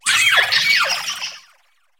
Cri de Triopikeau dans Pokémon HOME.